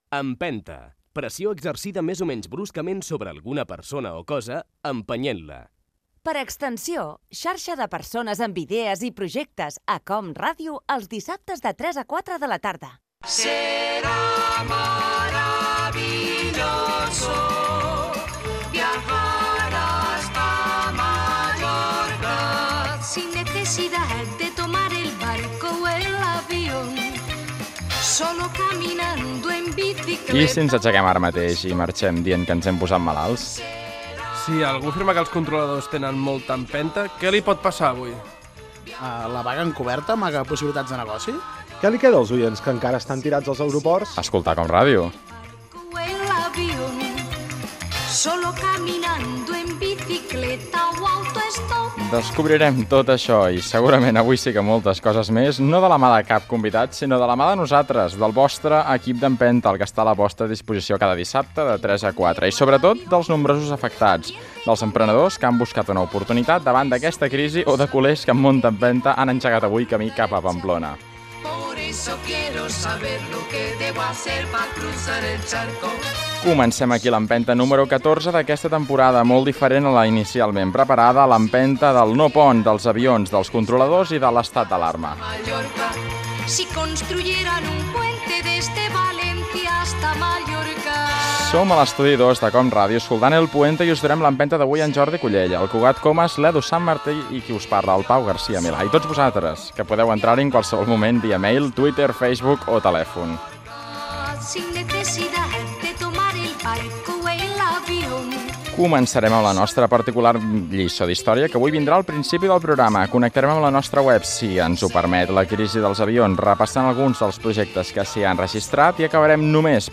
Careta i inici del programa dedicat a nous emprenedors. Comentari sobre la vaga de controladors, equip, sumari, telèfon i formes de contactar amb el programa
Fragment extret de l'arxiu sonor de COM Ràdio.